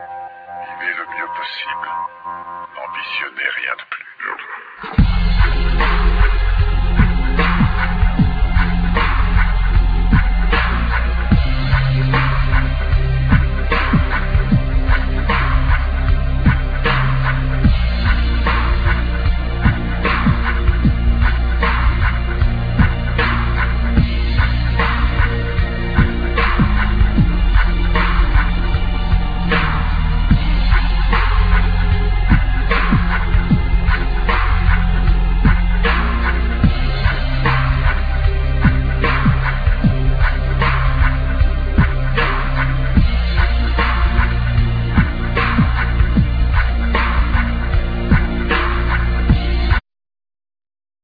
Vocal,Programming
Cello
Congas,Doundoun,Ti-bois
Kit percussions,Doudoumba
Gaida
Tin whistle
Dof